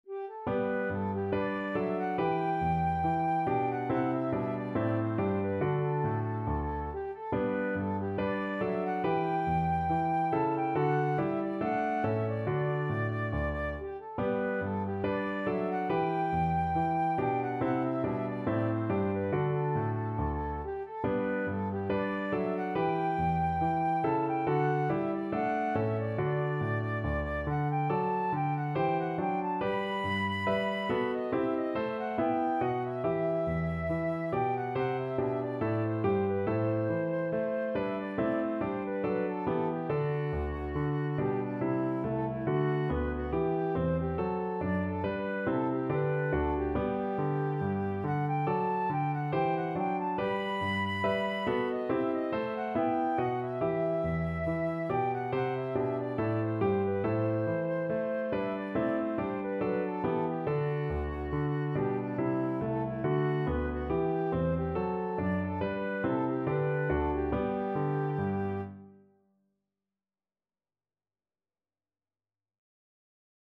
Flute
G major (Sounding Pitch) (View more G major Music for Flute )
Classical (View more Classical Flute Music)
handel_gavotte_hwv491_FL.mp3